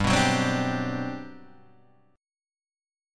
G Major 11th